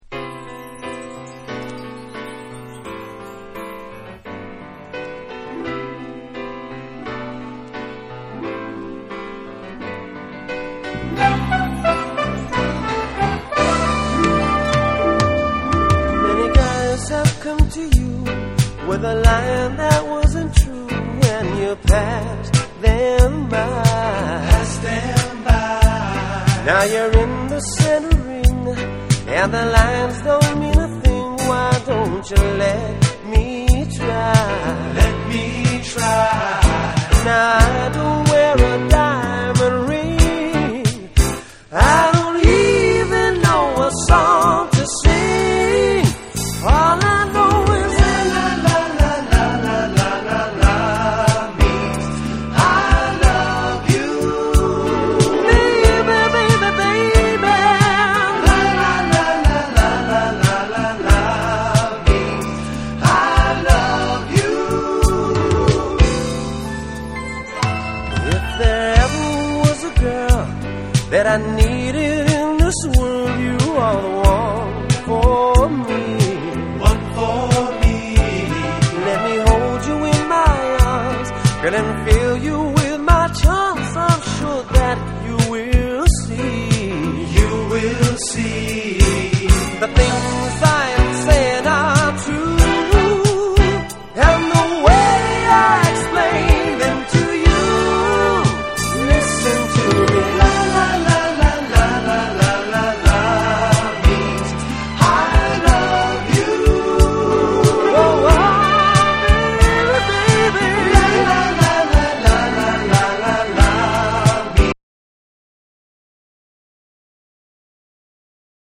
BREAKBEATS / ORGANIC GROOVE / WORLD